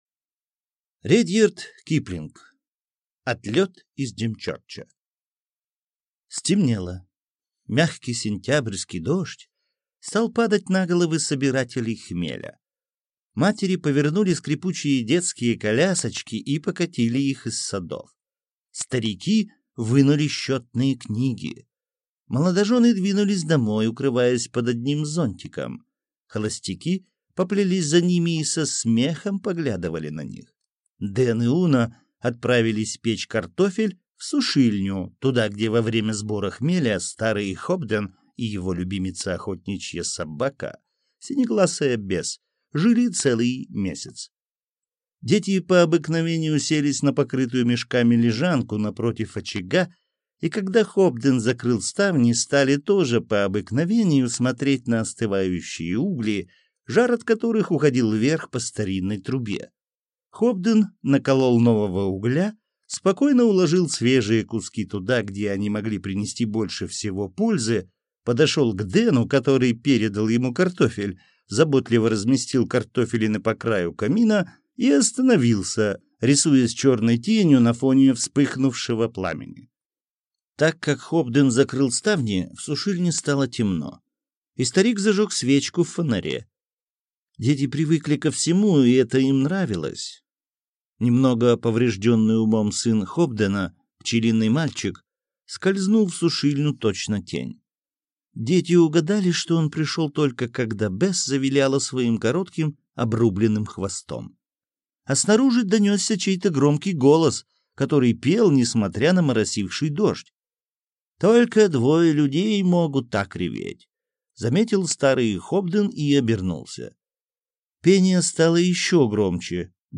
Аудиокнига Отлет из Димчерча | Библиотека аудиокниг